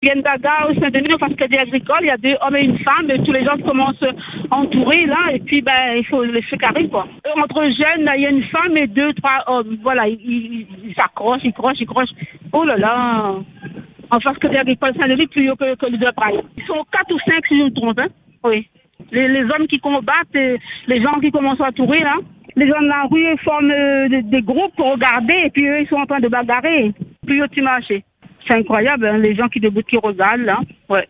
Cette Dionysienne, présente au moment des faits, raconte ce qu’elle a vu.